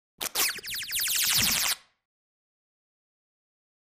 Beeps Sci-Fi Space Machine Beep 2